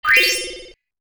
UI_SFX_Pack_61_9.wav